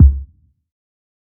TC3Kick19.wav